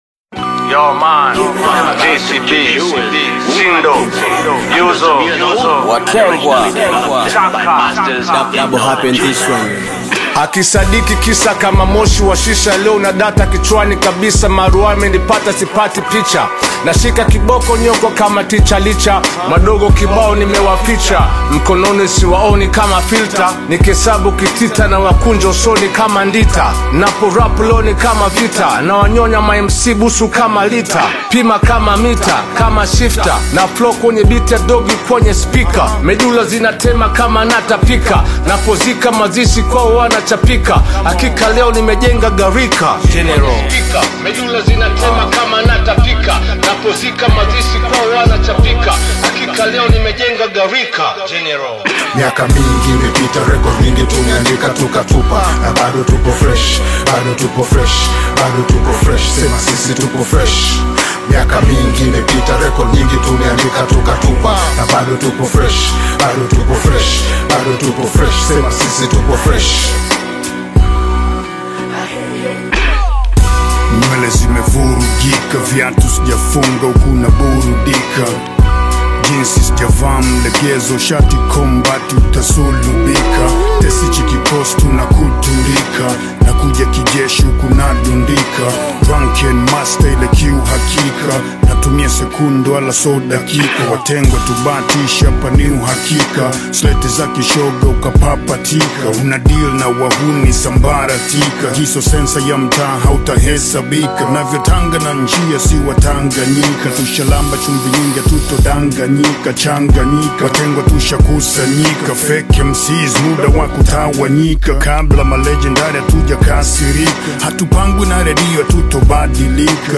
AudioHip Hop
is a nostalgic Hip Hop track